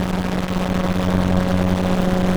ELECTRICITY_Distorted_loop_mono.wav